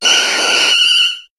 Cri de Rafflesia dans Pokémon HOME.